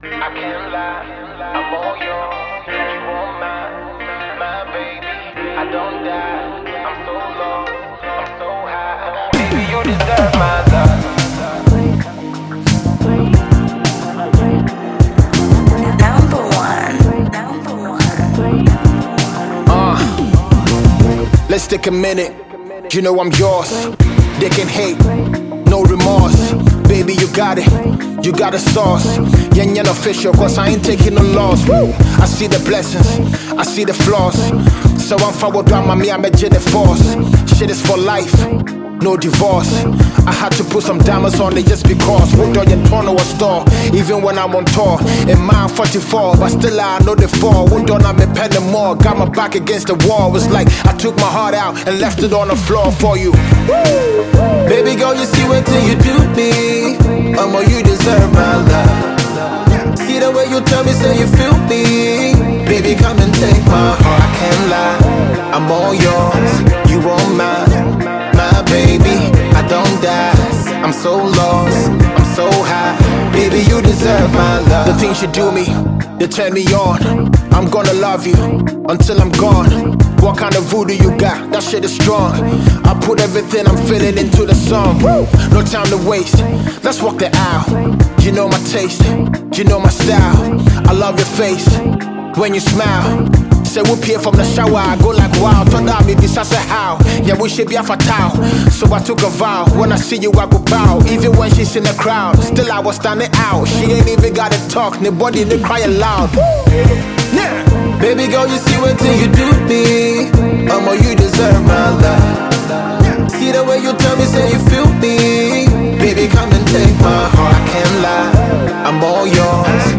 Ghana Music Music
Ghanaian Heavyweight rapper